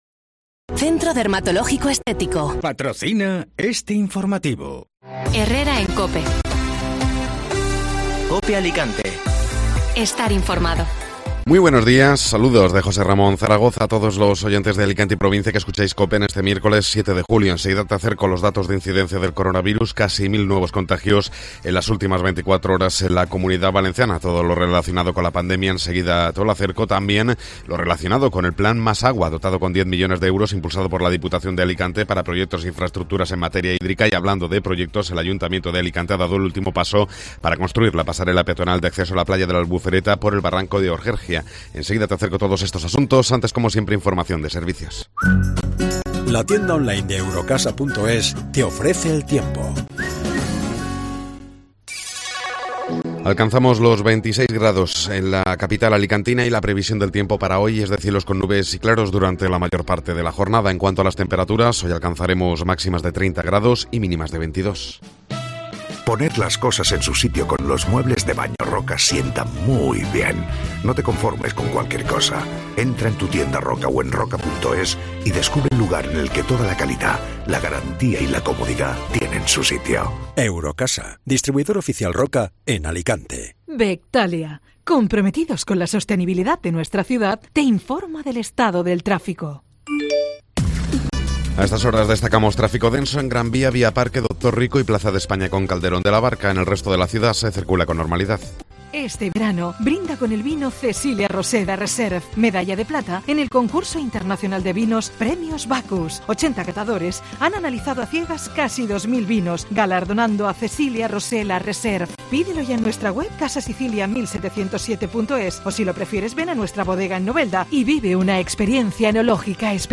Informativo Matinal (Miércoles 7 de Julio)